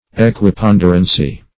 Search Result for " equiponderancy" : The Collaborative International Dictionary of English v.0.48: Equiponderance \E`qui*pon"der*ance\, Equiponderancy \E`qui*pon"der*an*cy\, n. [Equi- + ponderance: cf. F. ['e]quipond['e]rance.]